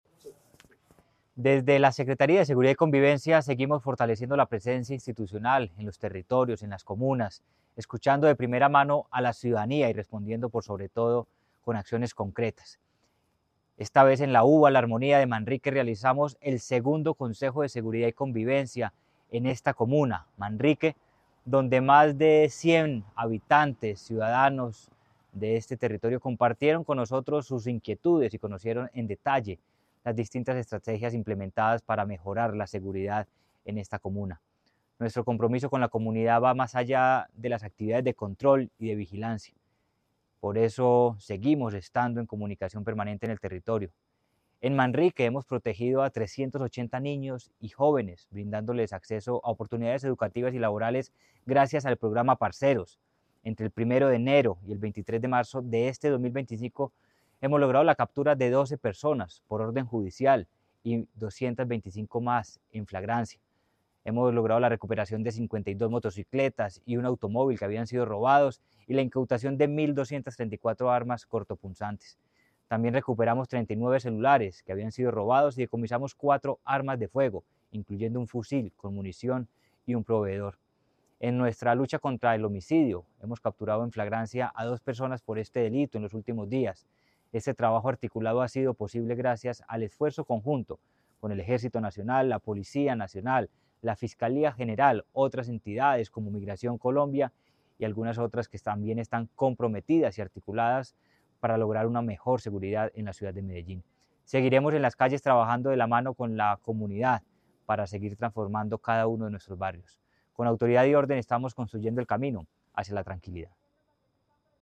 Audio Palabras de Manuel Villa Mejía, secretario de Seguridad y Convivencia En la UVA La Armonía del barrio Manrique, se realizó el segundo Consejo de Seguridad y Convivencia de esa parte de Medellín.